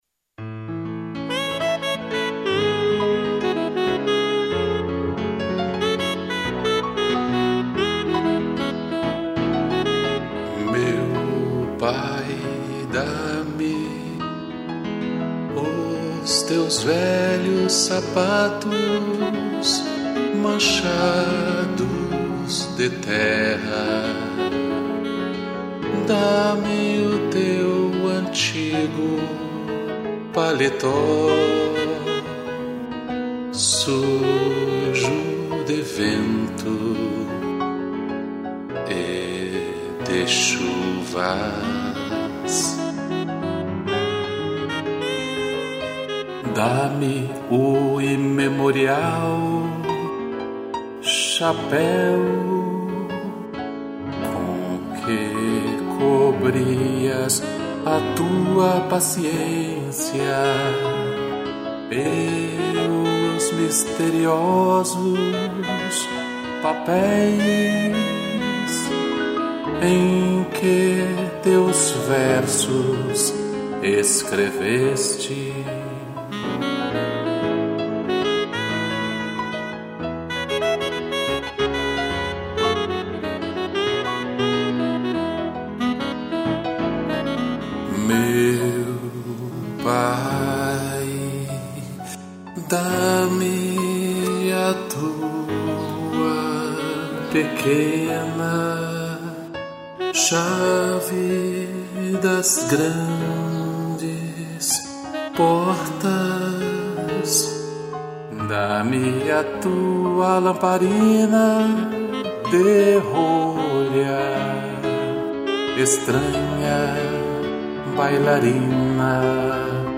2 pianos de sax